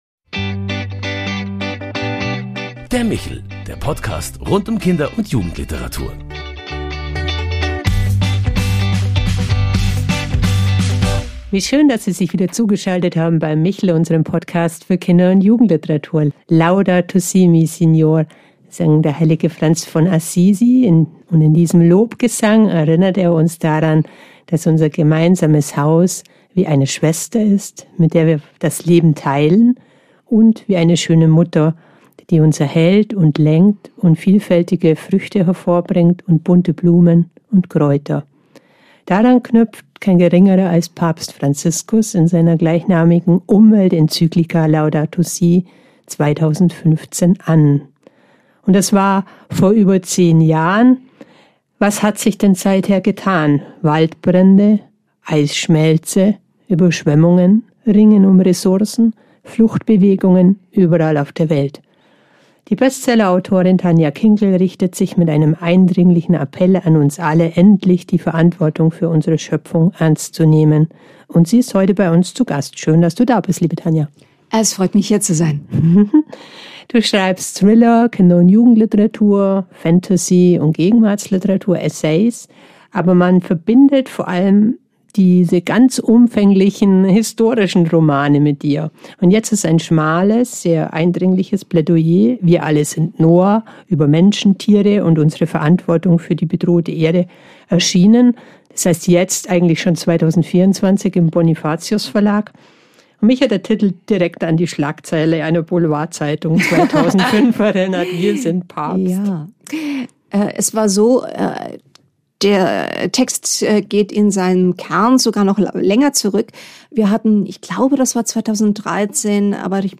In dieser besonderen Folge des MICHEL-Podcasts ist die renommierte Autorin Tanja Kinkel zu Gast, die mit ihrem aktuellen Werk „Wir alle sind Noah“ ein leidenschaftliches Plädoyer für ökologische und ethische Verantwortung veröffentlicht hat.
Kinkel spricht eindringlich darüber, wie sich ihre Wahrnehmung von Natur und Verantwortung über die Jahrzehnte gewandelt hat – von der ersten Begegnung mit dem sauren Regen bis hin zur schockierenden Erfahrung, dass Gletscher einfach verschwinden. Dabei geht es nicht nur um die Umwelt, sondern auch um unsere Haltung gegenüber Tieren, Mitmenschen und zukünftigen Generationen.